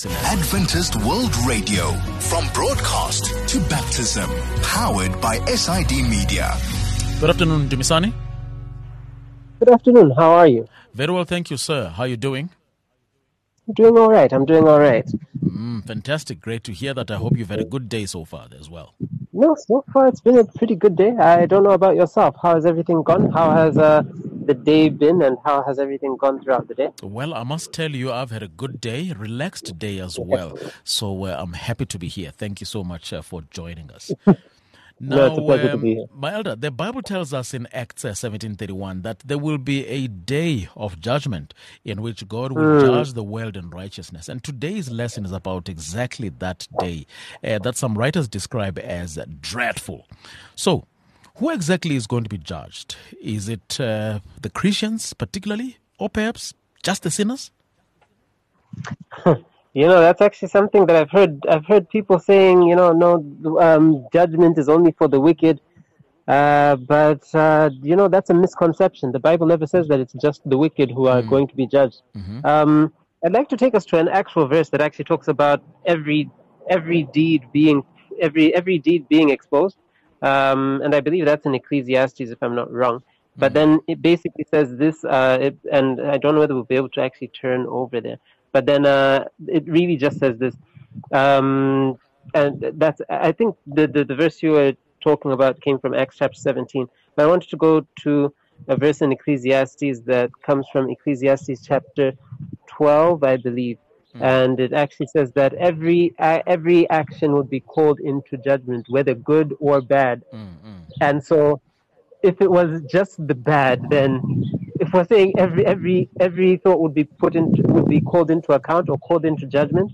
The Bible tells us in Act 17:31 that there will be a day of judgement, in which God will judge the world in righteousness. Today’s lesson is about that day that some writers describe as “dreadful”.